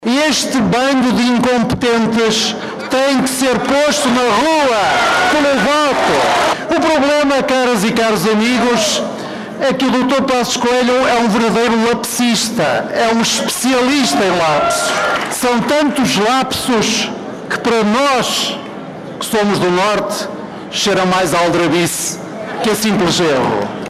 António Costa candidato a primeiro ministro esteve ontem em Vila Real, num comício onde o Governo de Passos Coelho foi fortemente criticado.